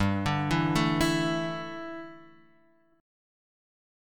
Dsus2/G chord